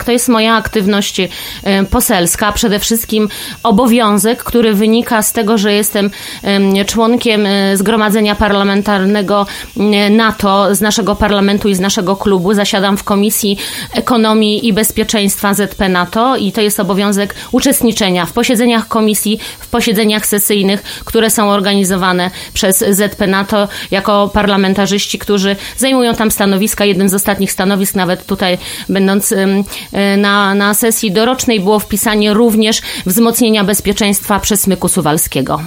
Temat poruszyliśmy podczas ostatniej wizyty Bożeny Kamińskiej w Radiu 5.  Posłanka tłumaczyła, że liczne podróże wynikają z jej obowiązków służbowych.